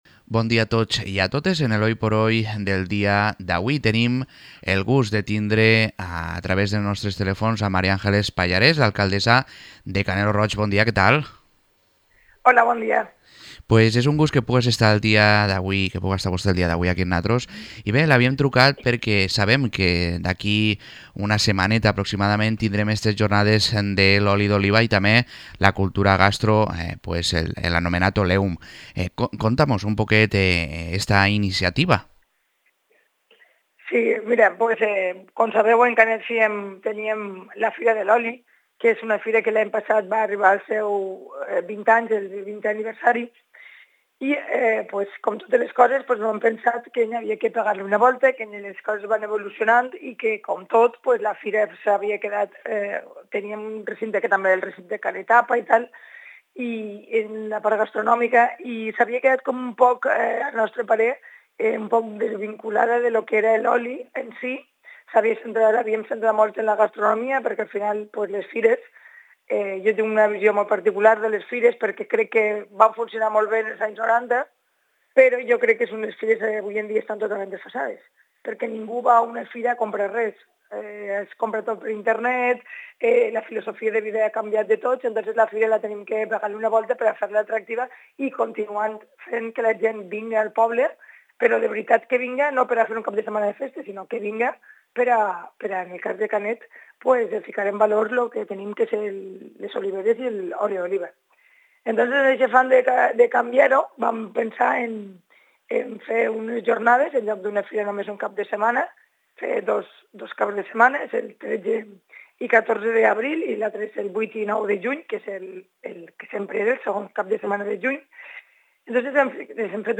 Podcast | Entrevista a Mari Ángeles Pallarés alcaldesa de Canet lo Roig